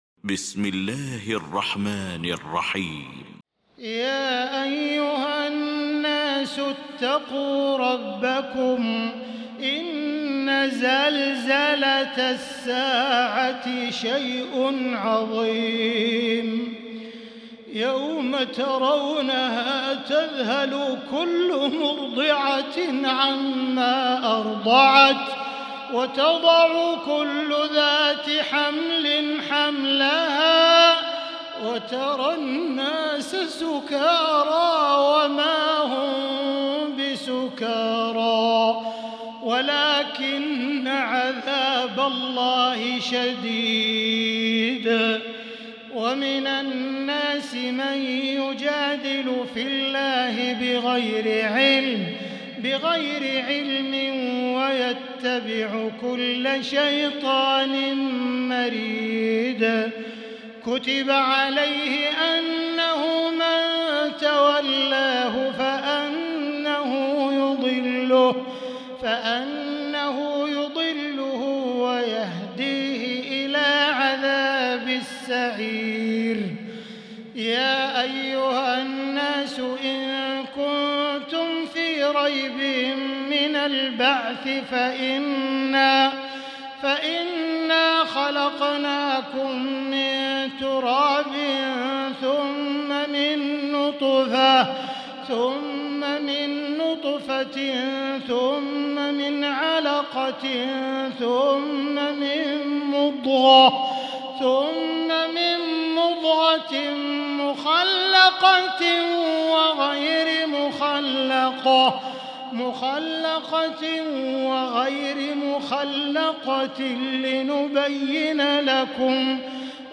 المكان: المسجد الحرام الشيخ: فضيلة الشيخ عبدالله الجهني فضيلة الشيخ عبدالله الجهني الحج